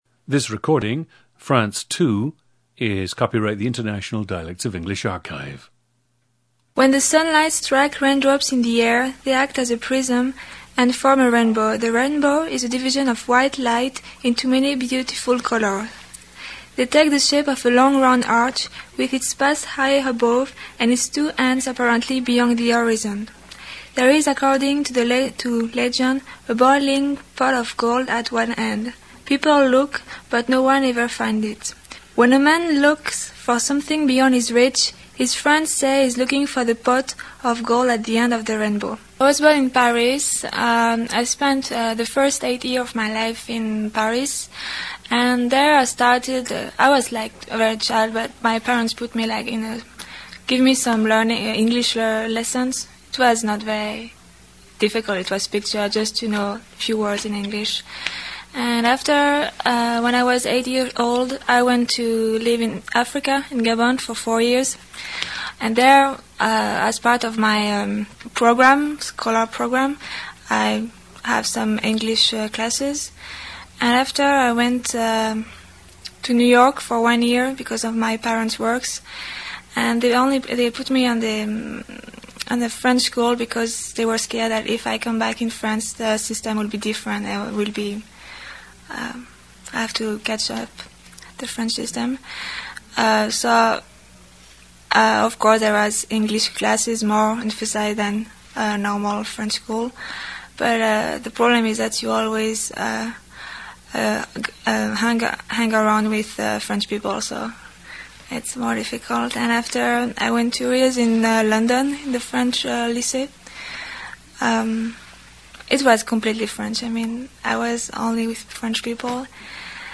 PLACE OF BIRTH: Paris, France
GENDER: female
She learned British English, the influences of which can be heard in the recording.